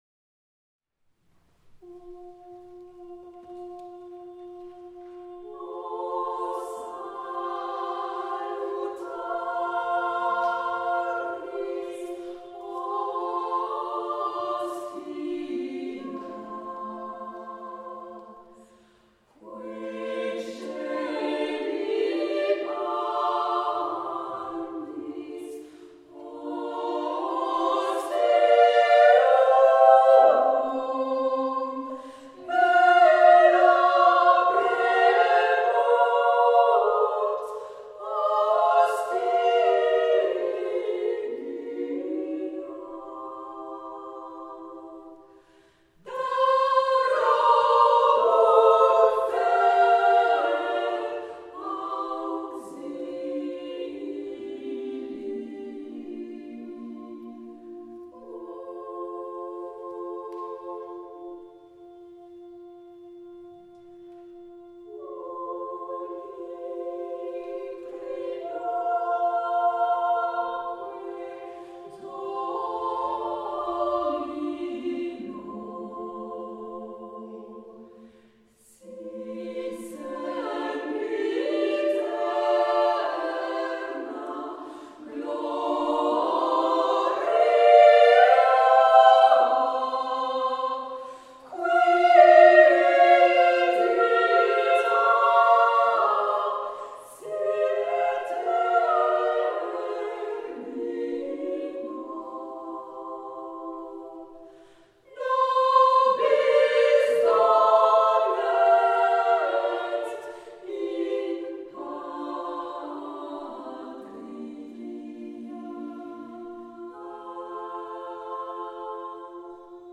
O Salutaris, tiré de la messe à trois voix d'André Caplet, enregistré à St-Sulpice.